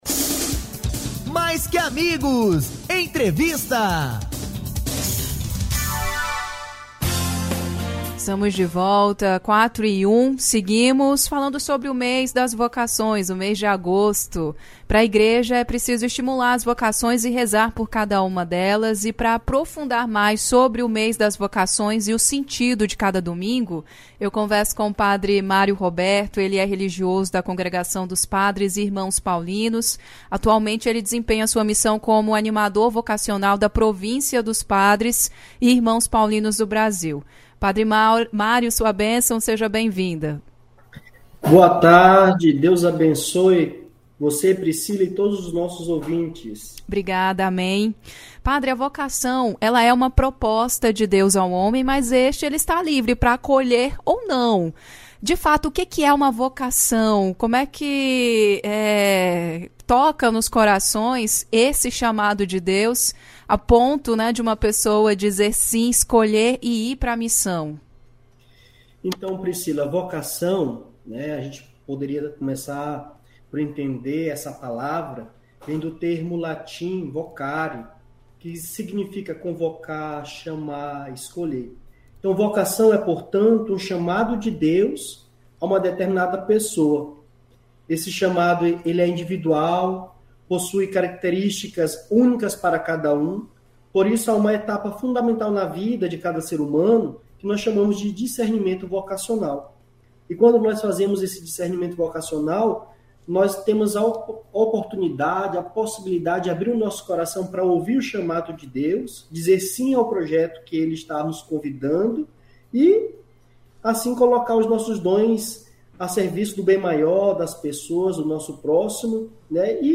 Na ocasião, ele falou sobre o mês vocacional.